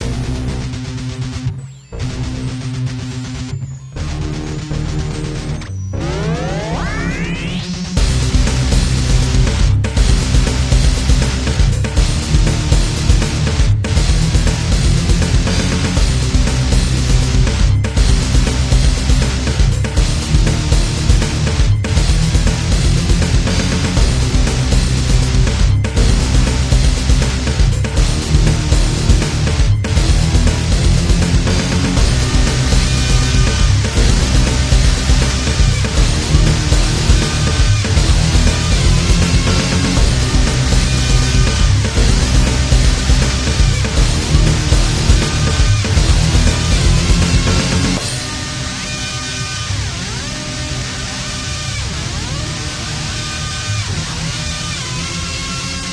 Clip 1 is the verse/chorus and clip 2 is the ending.